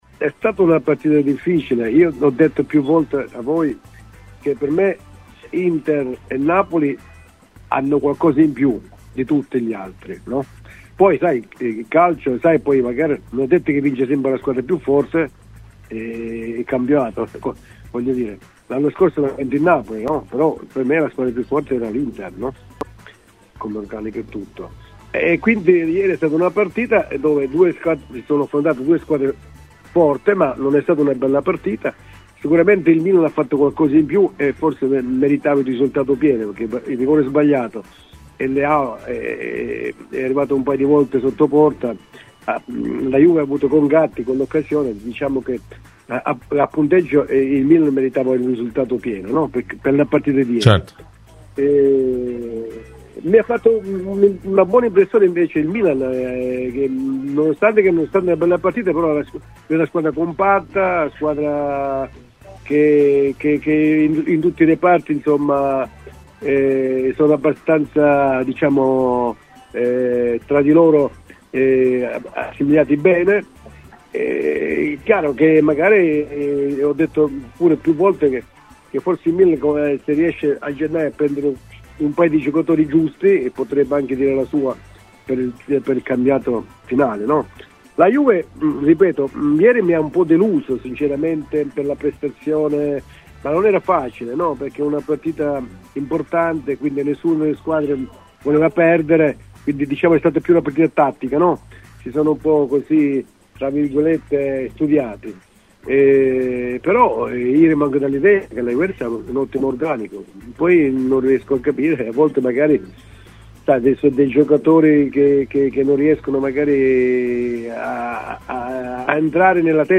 intervenuto a Radio Bianconera nel corso della trasmissione La Juve del Lunedì